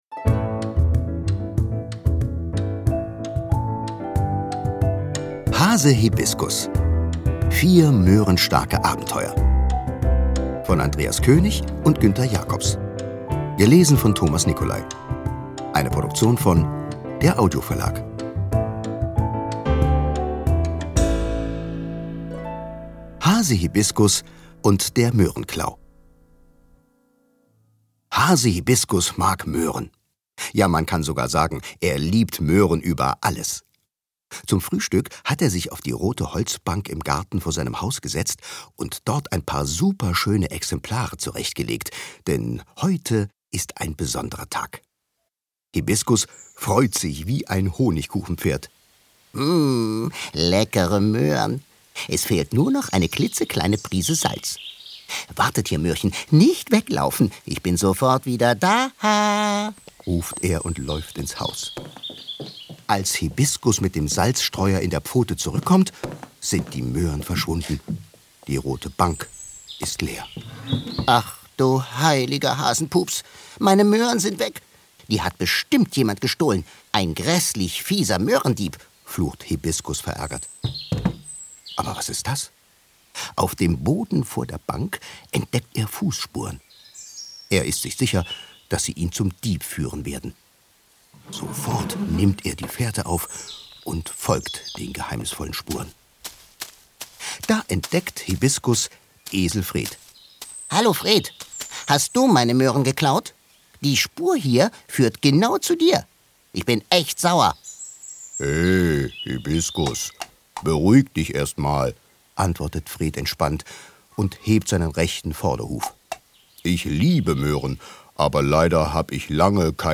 Ungekürzte szenische Lesungen mit Musik mit Thomas Nicolai (1 CD)
Thomas Nicolai (Sprecher)
Der Entertainer wird auch »Mann mit den tausend Stimmen« genannt.